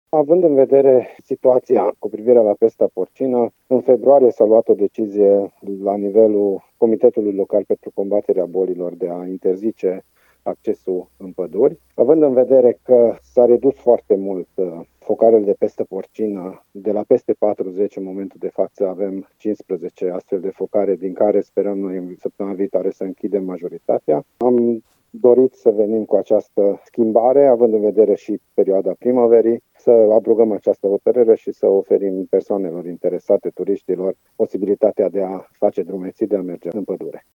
Prefectul Toth Csaba spune că în urma reducerii numărului de focare de pestă porcină, de la peste 40 la 15 focare, care se speră că vor fi închise în mare parte săptămâna viitoare, s-a luat decizia de a le permite turiștilor să facă drumeții și să meargă în pădurile arădene,